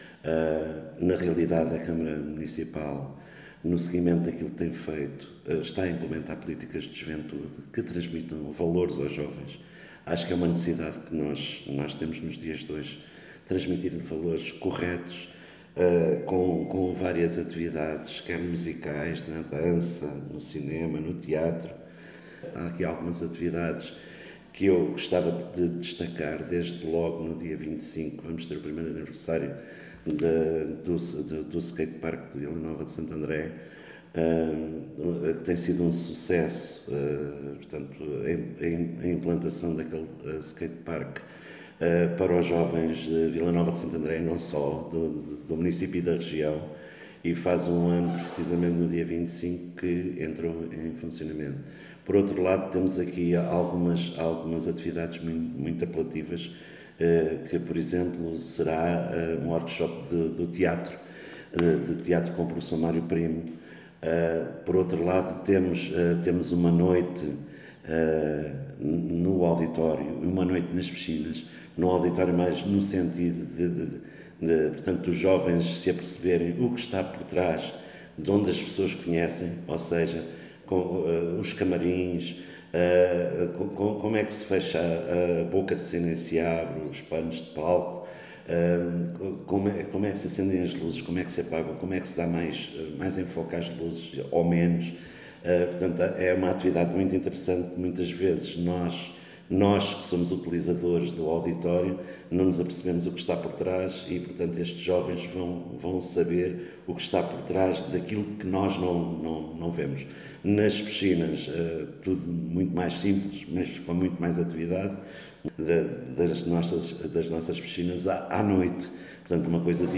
Para ouvir o Vereador da Juventude da Câmara Municipal, Jaime Cáceres sobre a Quinzena da Juventude 2018